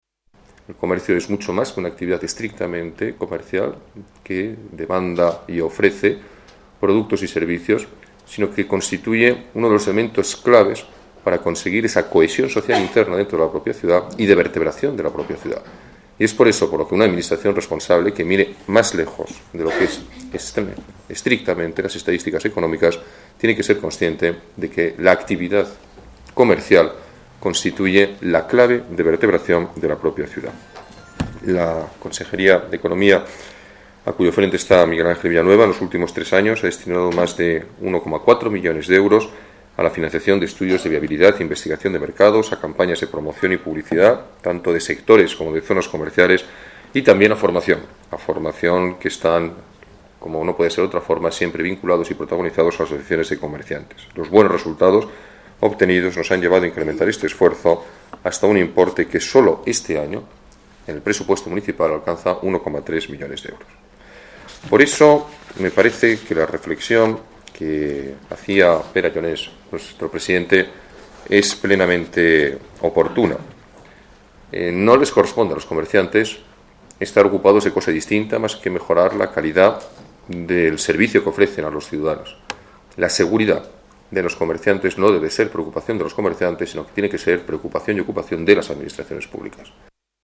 Gallardón destaca en la clausura de la Jornada de Comercio y Seguridad la importancia del sector en el crecimiento de la ciudad